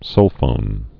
(sŭlfōn)